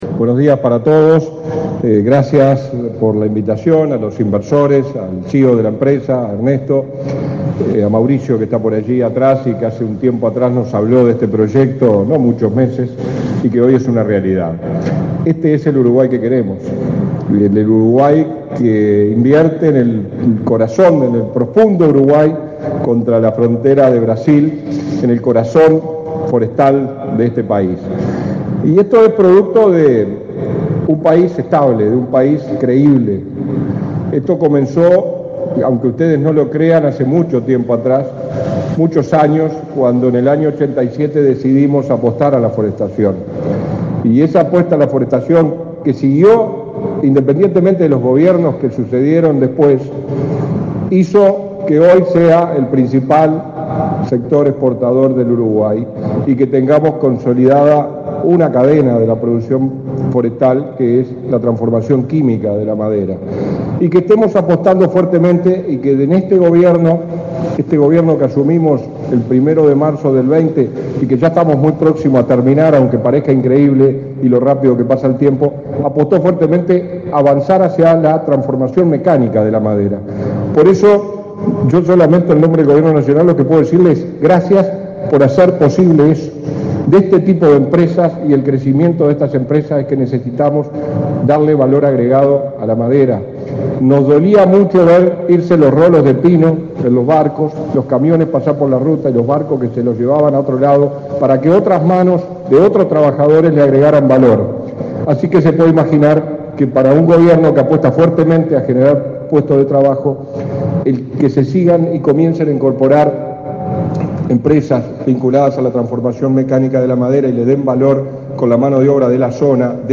Palabras del viceministro del MIEM, Walter Verri
Palabras del viceministro del MIEM, Walter Verri 09/02/2024 Compartir Facebook X Copiar enlace WhatsApp LinkedIn El viceministro del Ministerio de Industria, Energía y Minería (MIEM), Walter Verri, participó, este 9 de febrero, en la inauguración de aserradero en Rivera.